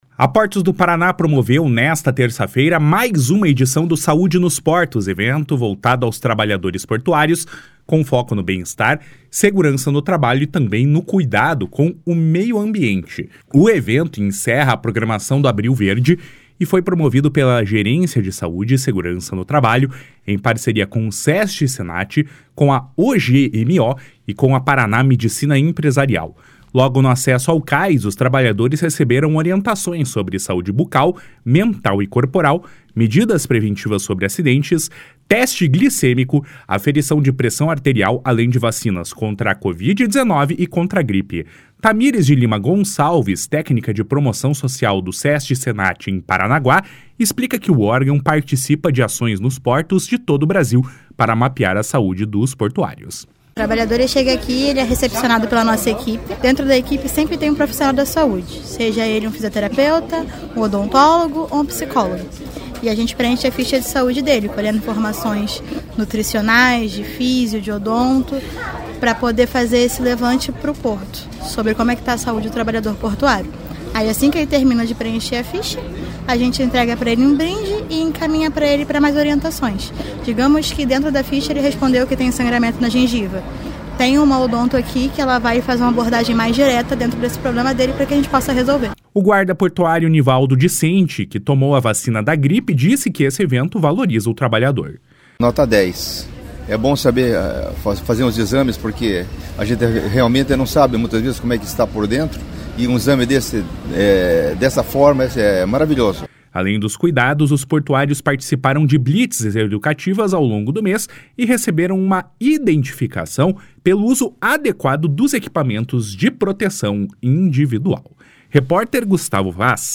Além dos cuidados, os portuários participaram de blitz educativas ao longo do mês e receberam uma identificação pelo uso adequado dos equipamentos de proteção individual. (Repórter